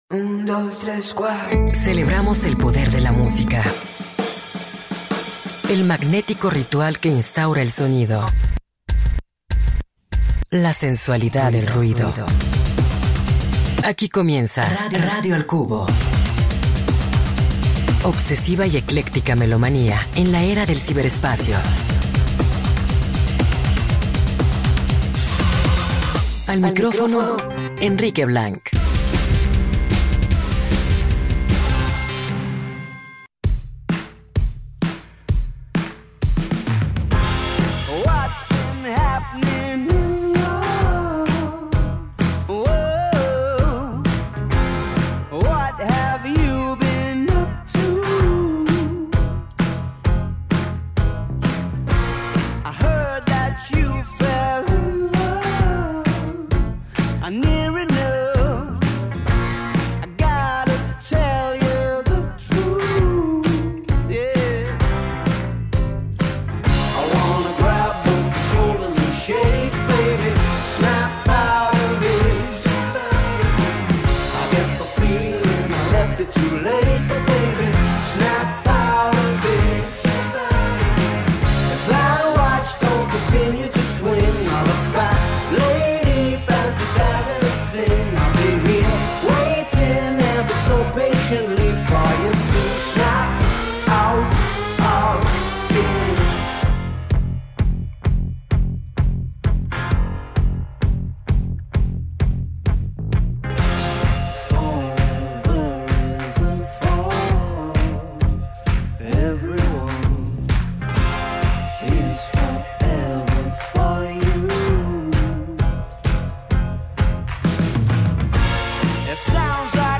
Una selección que vibra y acompaña.